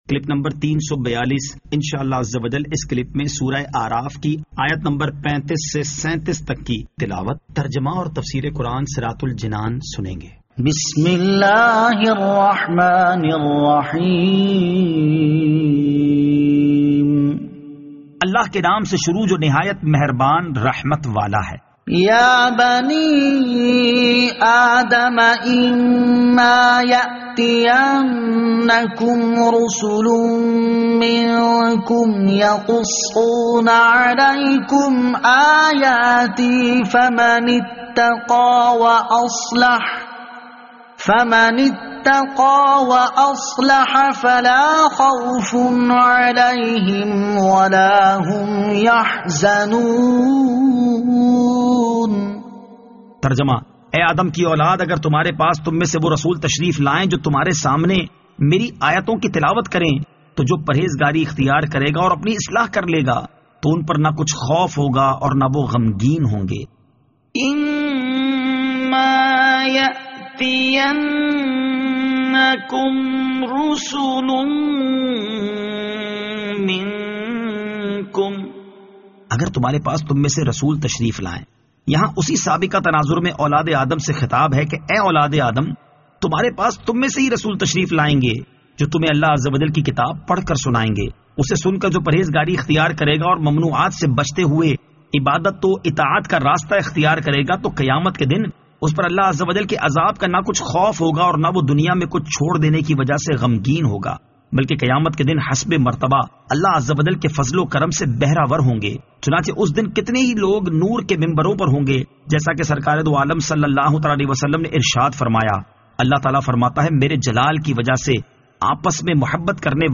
Surah Al-A'raf Ayat 35 To 37 Tilawat , Tarjama , Tafseer